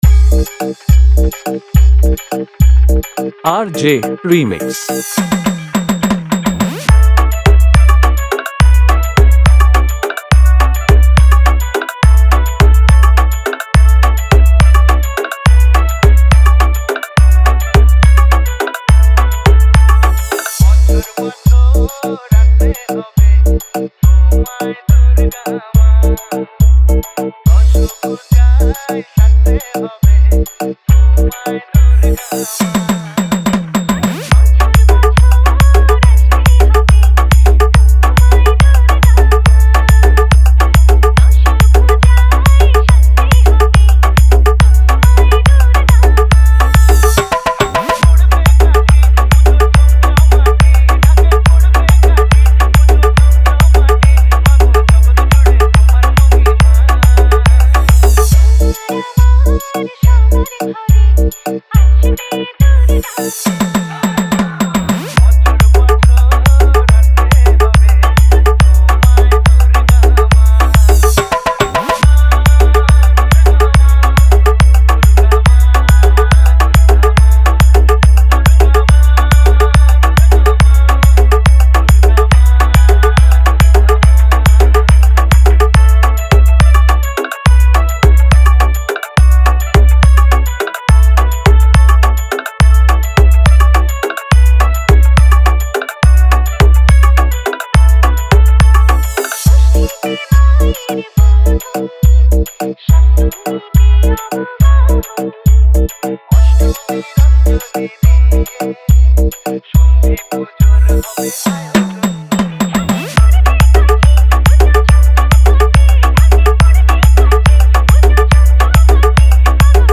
দুর্গ উৎসব 2024 স্পেশাল হামবিং ভক্তি বাংলা গান